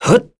Esker-Vox_Jump.wav